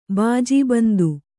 ♪ bājī bandu